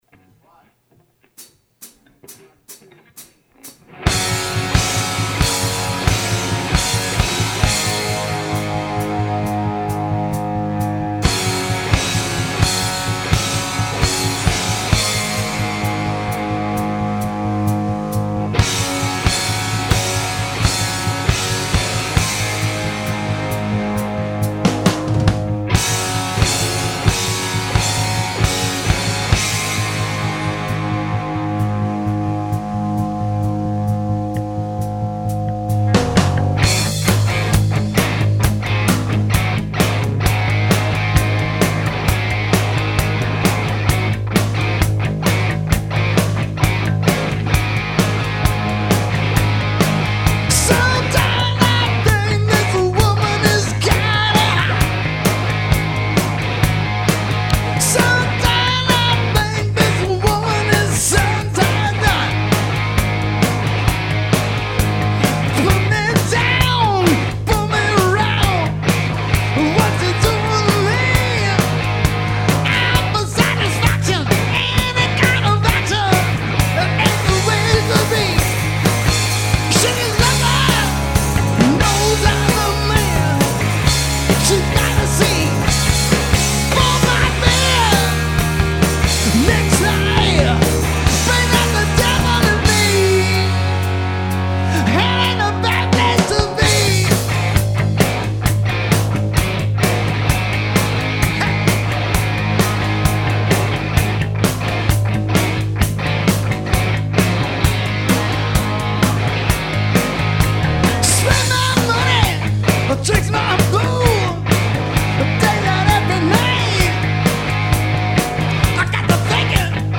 I dubbed a couple of guitar tracks on this for an amp demo.